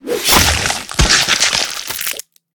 slash.ogg